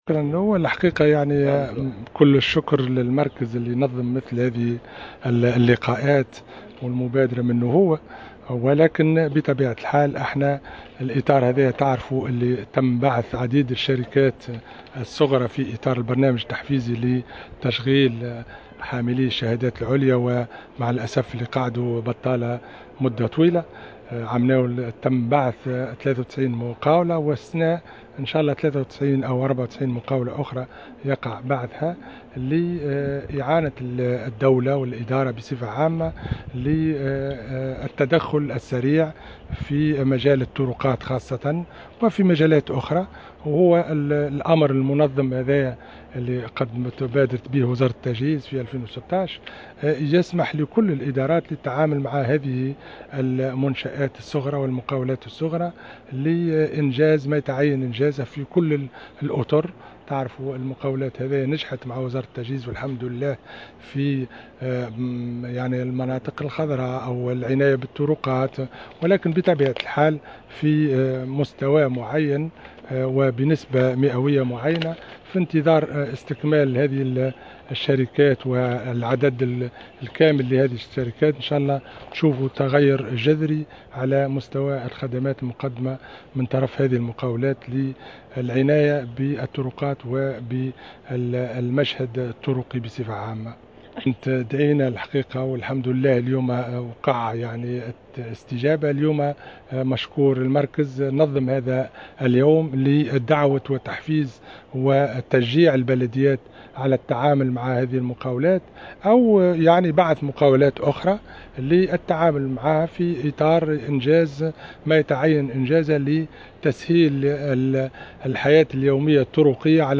أكد وزير التجهيز محمد صالح العرفاوي في تصريح لمراسلة الجوهرة "اف ام" اليوم الأربعاء على هامش الملتقى الإقليمي الأول حول دعم العمل البلدي عن طريق المؤسسات الصغرى لأصحاب الشهائد العليا أن هذه المبادرة تندرج في اطار البرنامج التحفيزي لحاملي الشهادات العليا الذين أمضوا فترة طويلة عاطلين عن العمل.